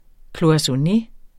Udtale [ kloɑsʌˈne ]